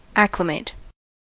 acclimate: Wikimedia Commons US English Pronunciations
En-us-acclimate.WAV